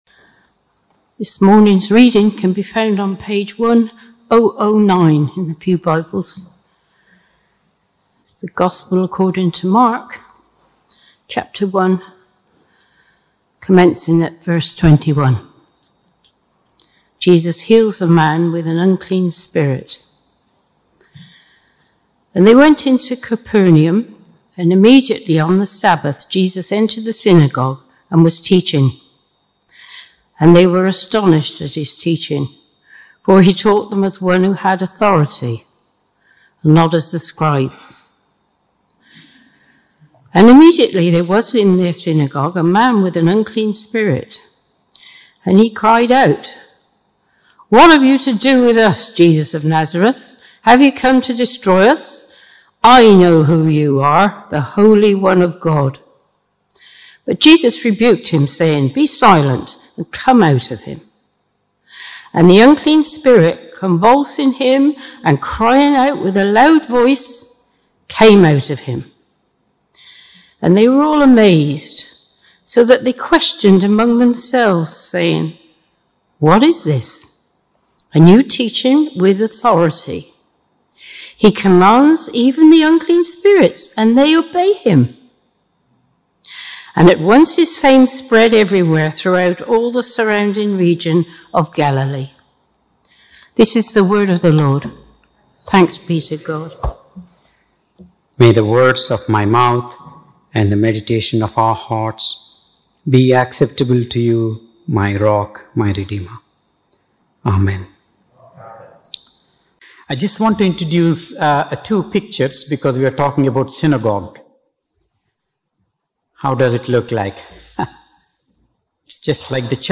Service Type: Morning Service Topics: Deliverance